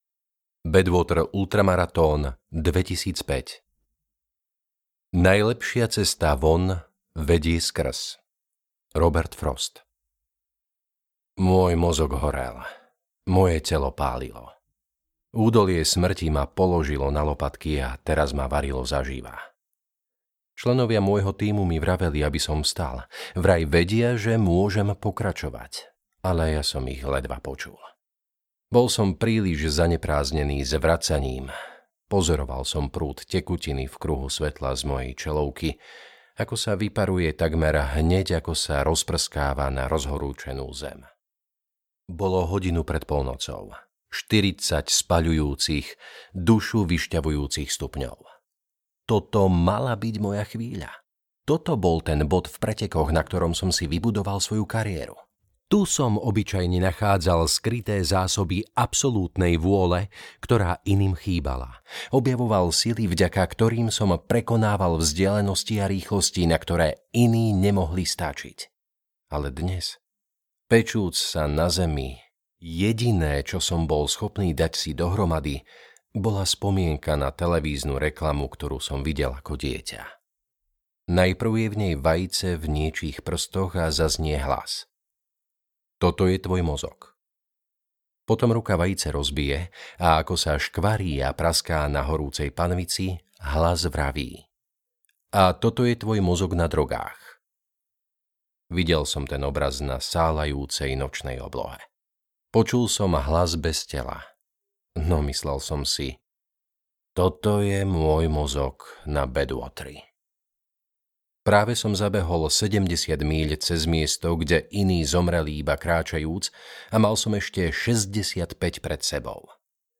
Jedz a behaj audiokniha
Ukázka z knihy